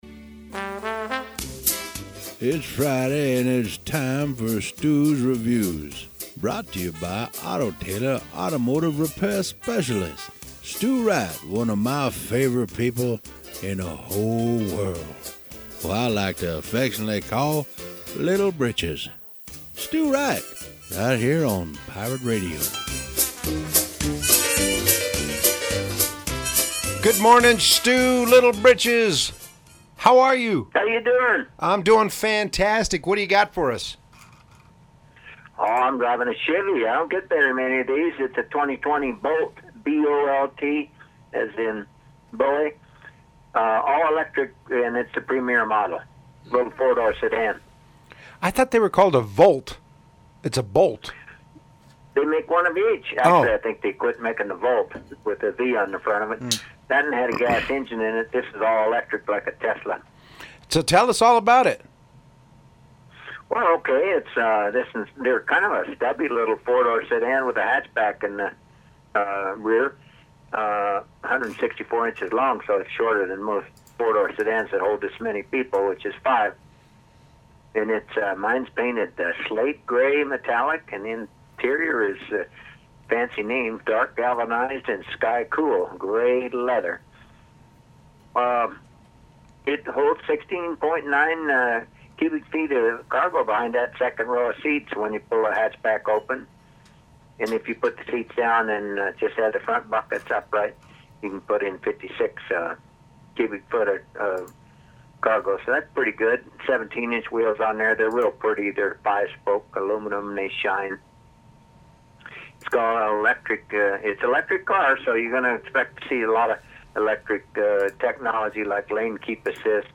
Radio On-Air Review: